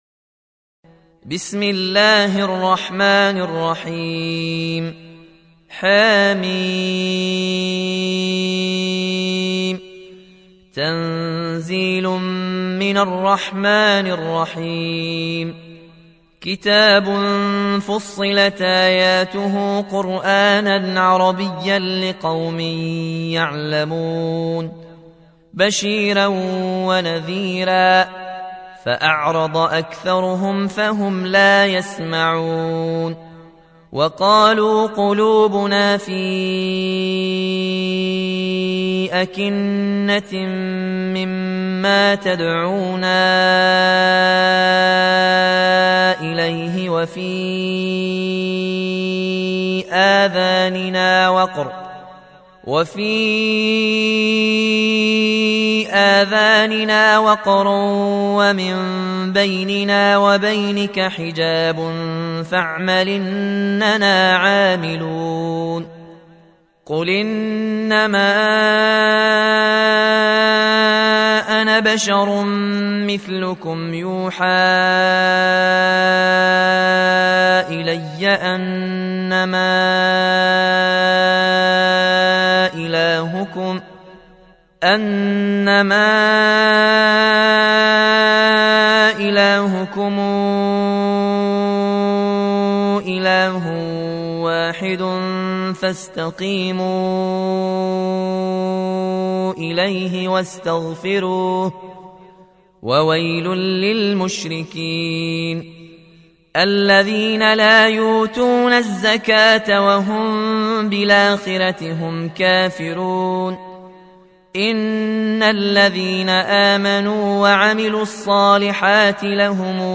Riwayat Warsh from Nafi